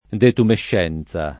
[ detumešš $ n Z a ]